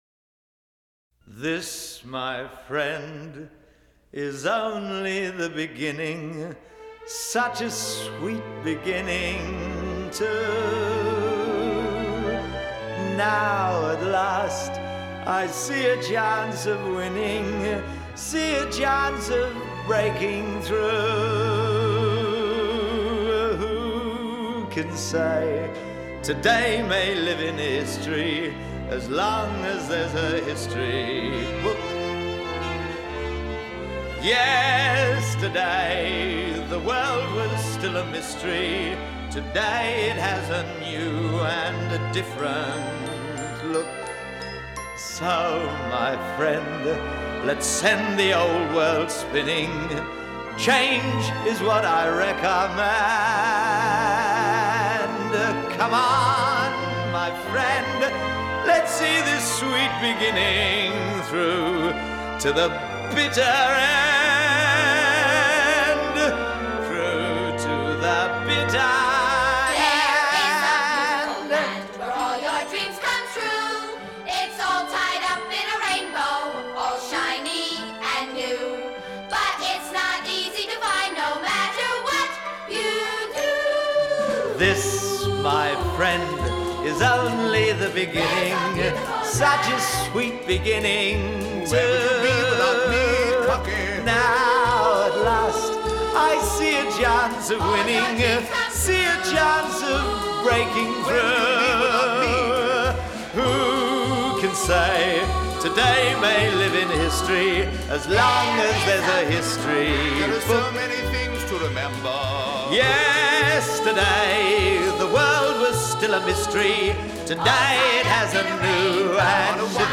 1964   Genre: Musical   Artist